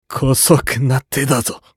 男性
厨二病ボイス～戦闘ボイス～